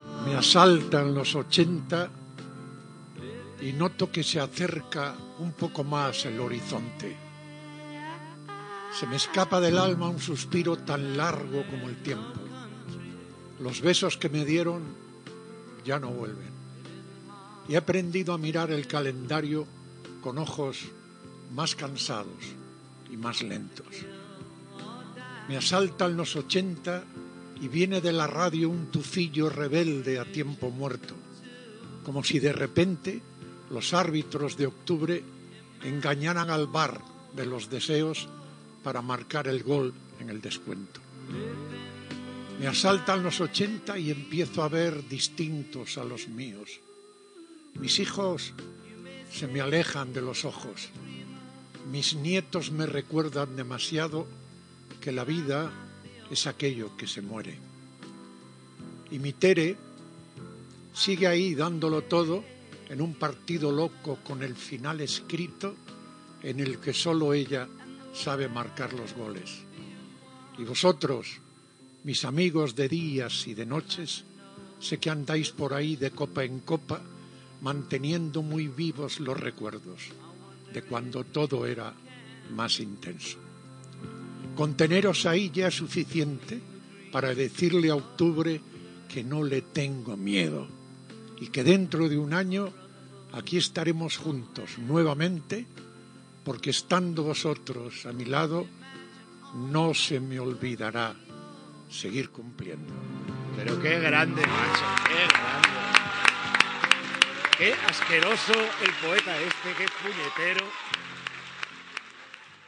Paraules a l'inici del programa de Pepe Domingo Castaño el dia que complia 80 anys
Esportiu